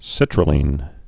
(sĭtrə-lēn)